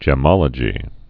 (jĕ-mŏlə-jē)